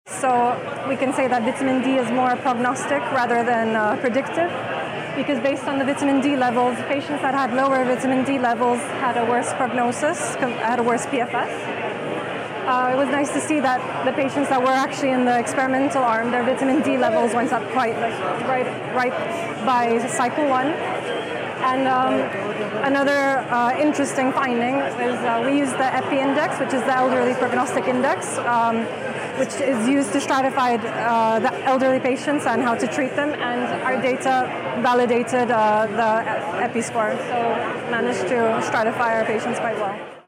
Presented at the 18th International Conference on Malignant Lymphoma; June 17-21, 2025; Lugano, Switzerland.